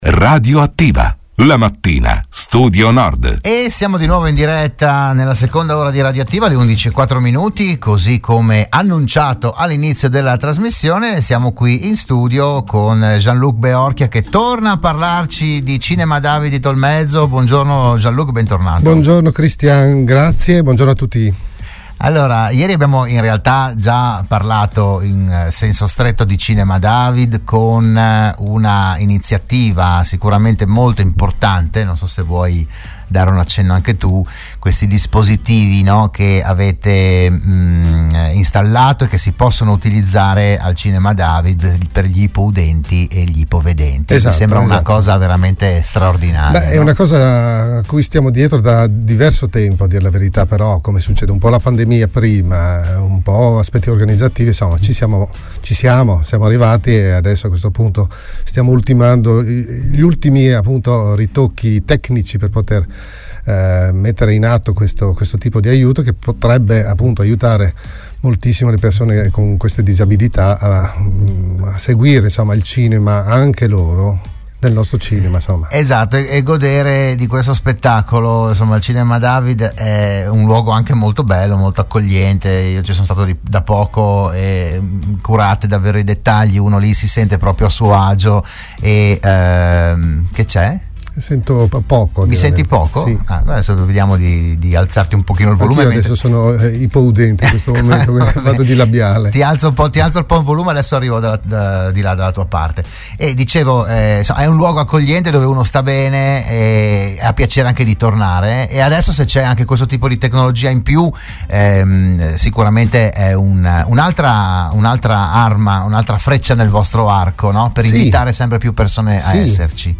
L'intervento a "RadioAttiva" di Radio Studio Nord